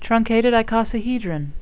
(trun-cat-ed   i-co-sa-he-dron)